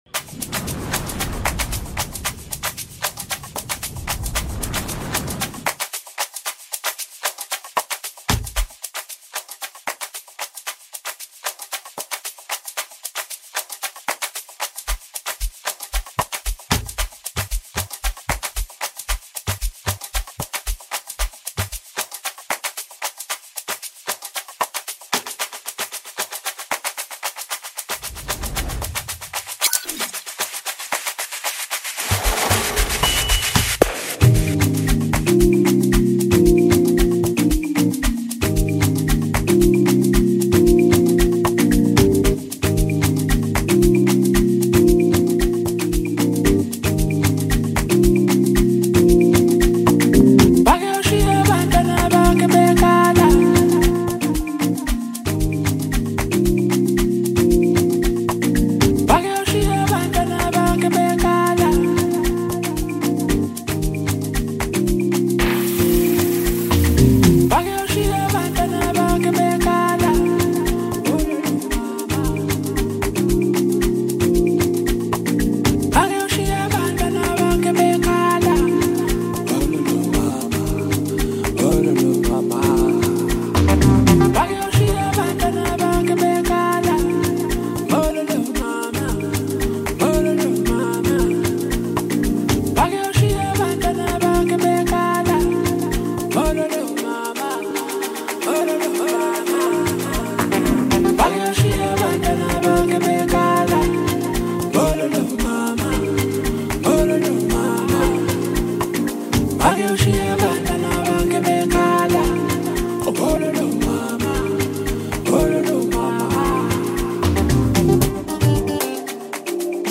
melodic sermons